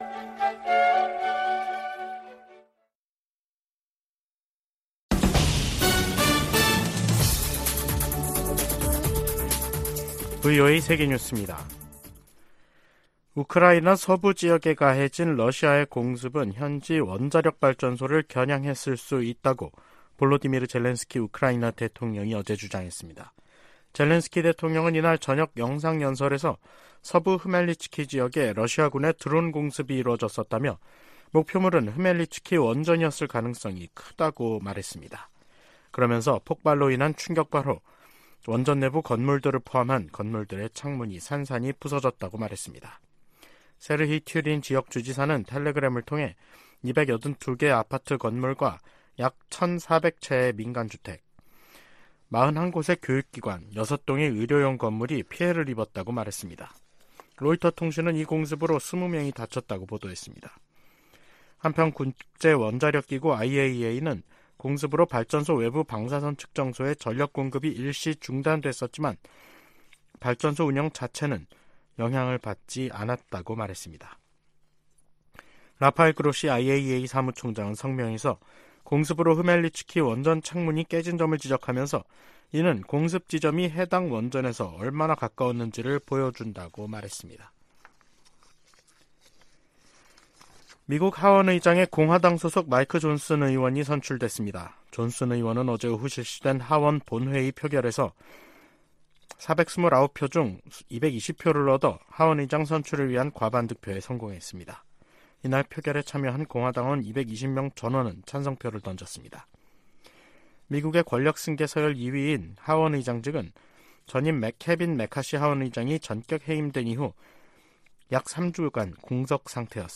VOA 한국어 간판 뉴스 프로그램 '뉴스 투데이', 2023년 10월 26일 2부 방송입니다. 미국, 한국, 일본 세 나라 외교장관들이 북한과 러시아 간 불법 무기 거래를 규탄하는 공동성명을 발표했습니다. 북한은 유엔에서 정당한 우주 개발 권리를 주장하며 사실상 우주발사체 발사 시도를 계속하겠다는 뜻을 내비쳤습니다.